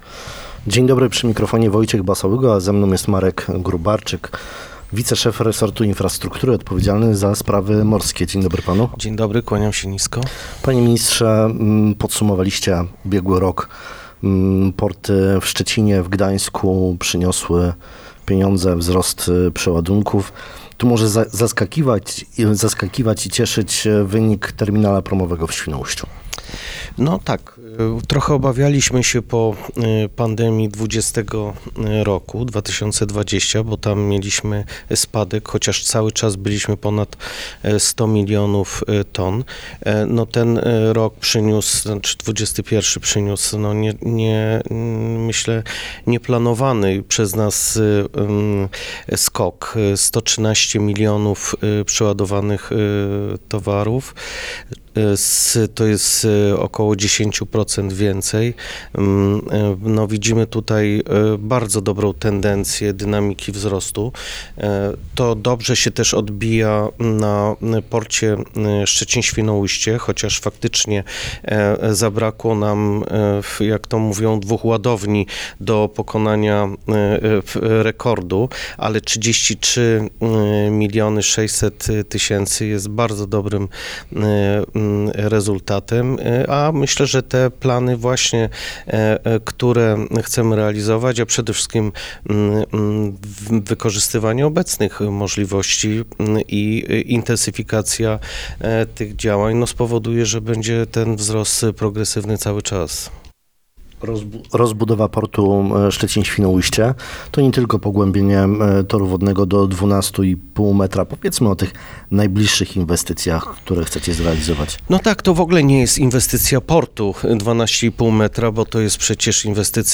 Naszym dzisiejszym gościem Rozmowy Dnia jest Marek Gróbarczyk – sekretarz stanu, pełnomocnik rządu ds. gospodarki wodą oraz inwestycji w gospodarce morskiej i wodnej. Opowiedział nam o podsumowaniu roku 2021 pod względem pracy portów, powiedział również o planowanych inwestycjach.